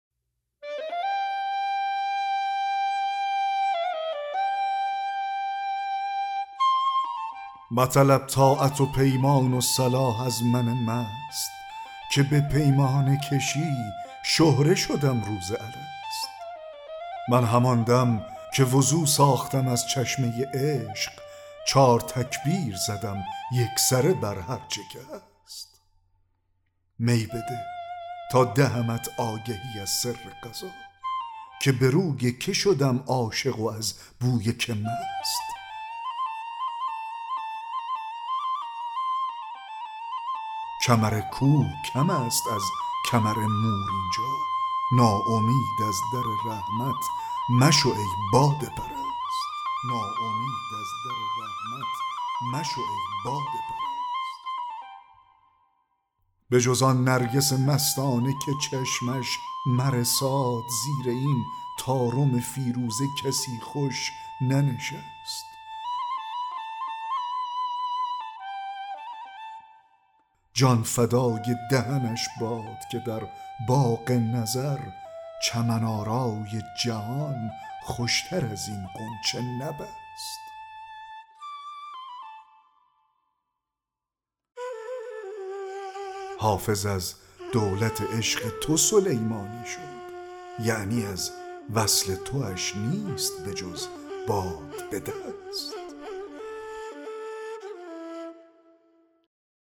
2- دکلمه غزل